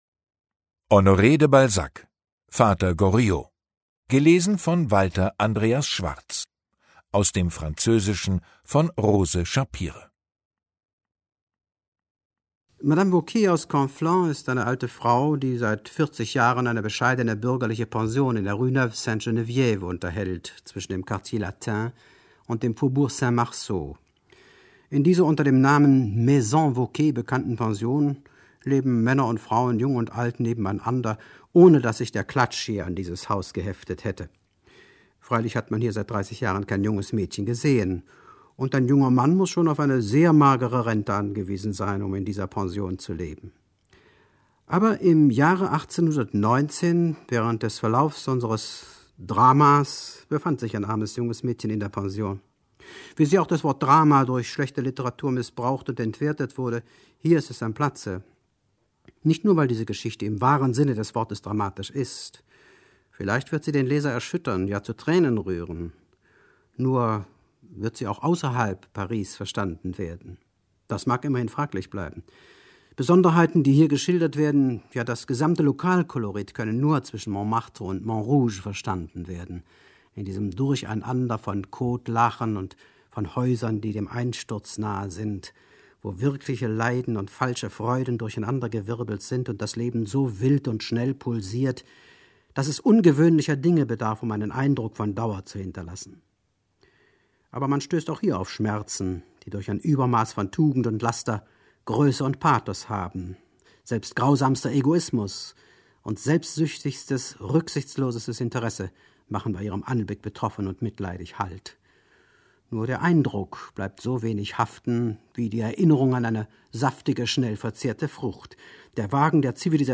Vater Goriot Ungekürzte Lesung mit Walter Andreas Schwarz
Walter Andreas Schwarz (Sprecher)